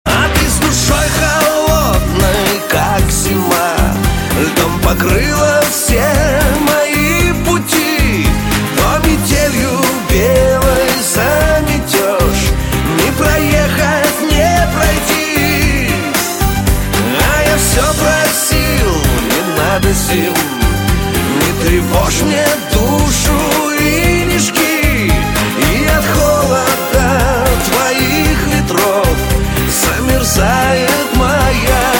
из Шансон